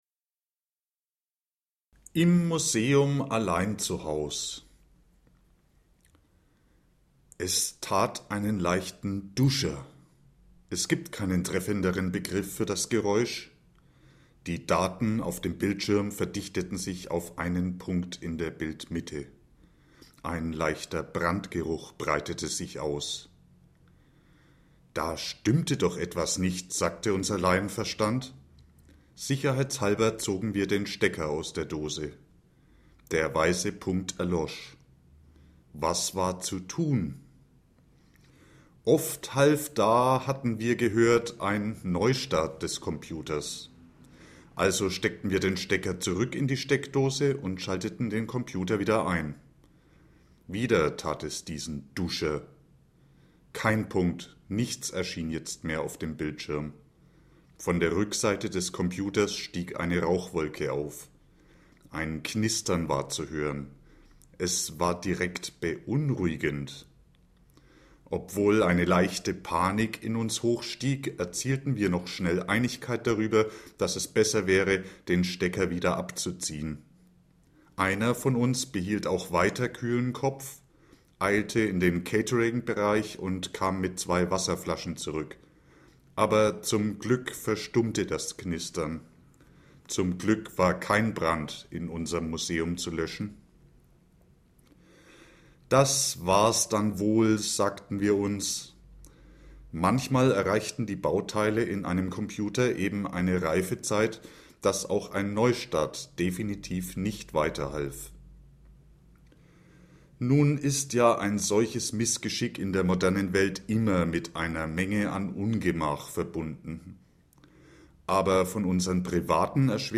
ImMuseumAlleinZuhausLeseprobe.mp3